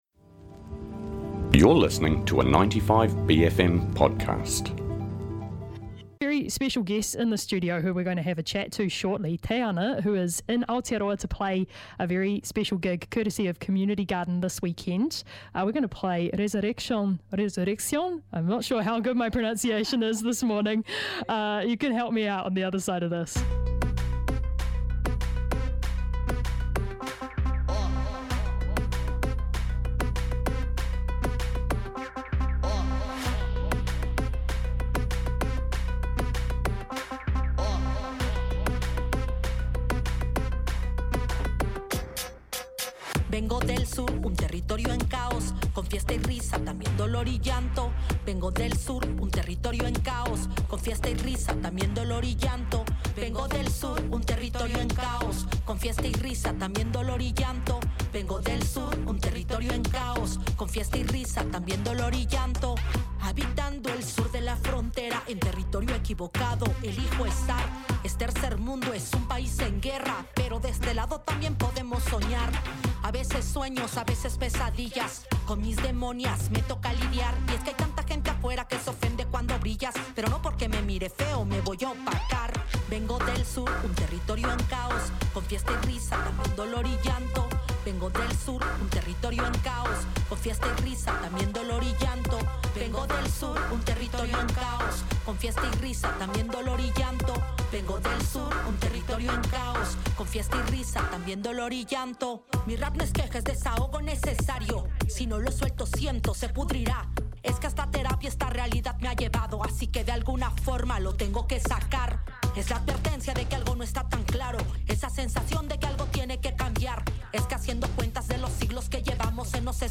I/V/ w Tayhana: 20 July, 2023